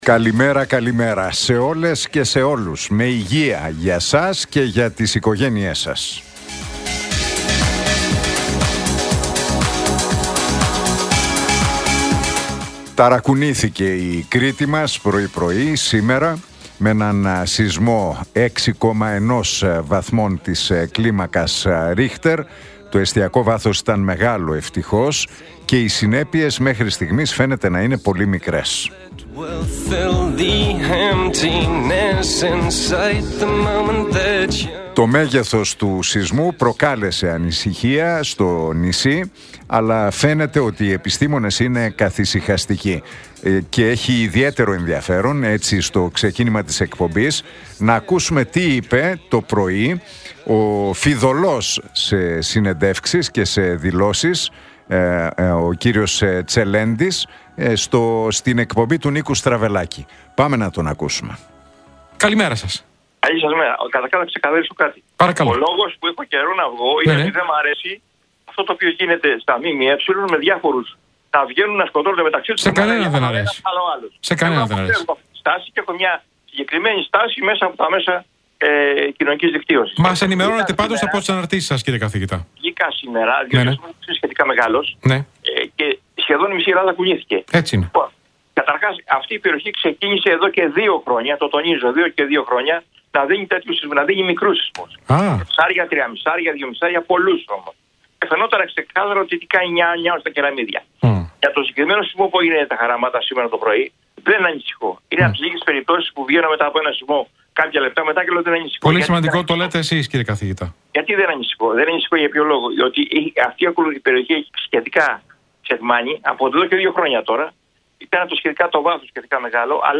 Ακούστε το σχόλιο του Νίκου Χατζηνικολάου στον ραδιοφωνικό σταθμό Realfm 97,8, την Πέμπτη 22 Μαΐου 2025.